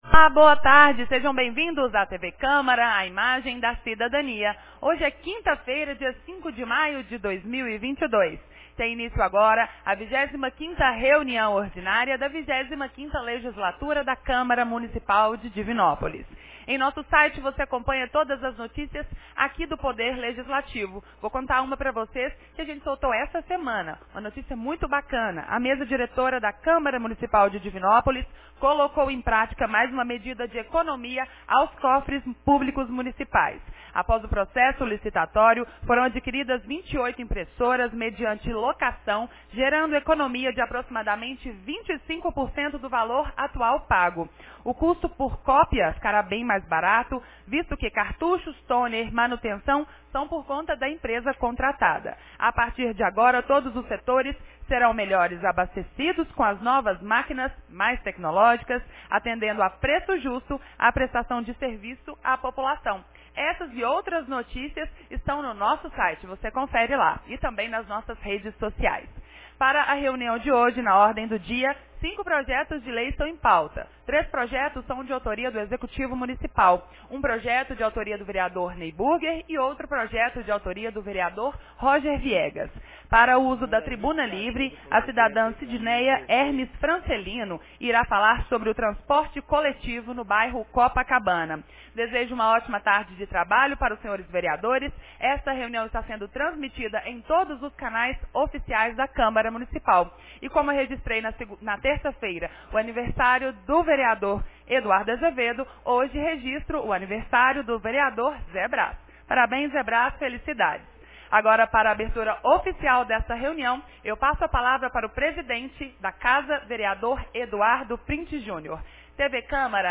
25ª Reunião Ordinária 05 de maio de 2022